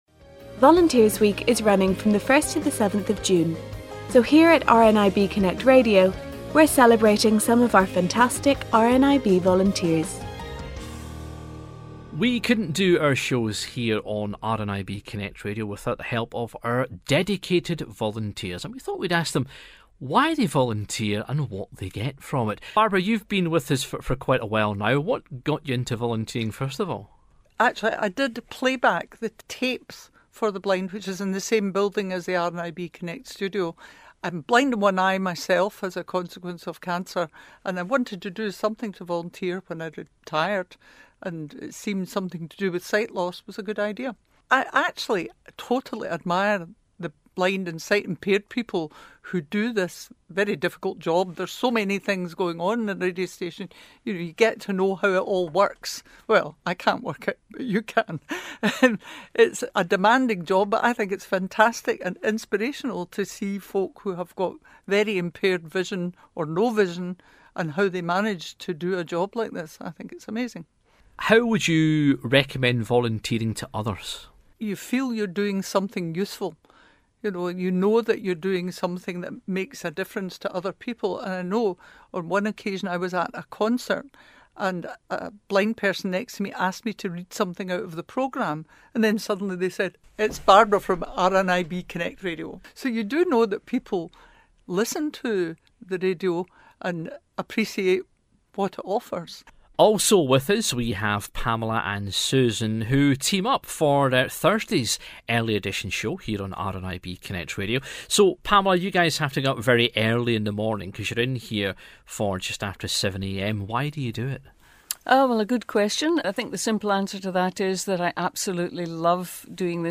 All this week we'll hear from volunteers who tell us why they got involved, what they get from working with RNIB and why they would recommend it to everyone.